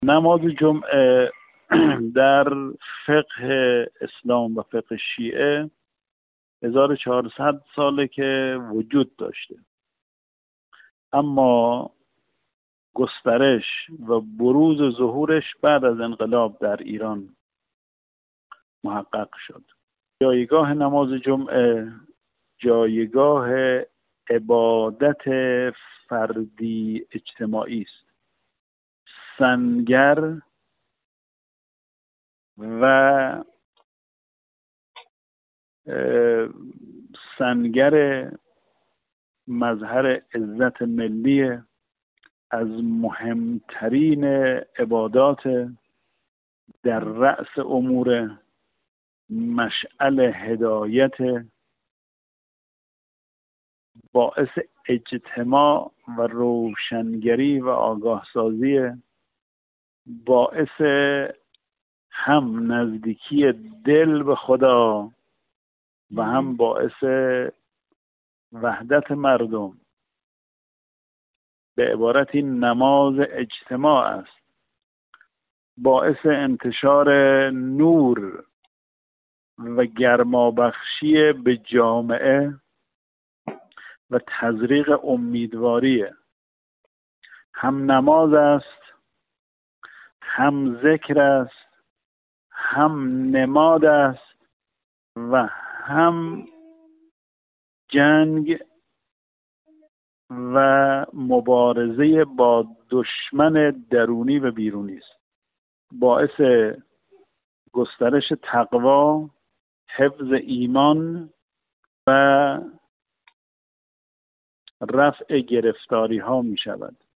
در گفت و گو با خبرنگار خبرگزاری رسا در خرم آباد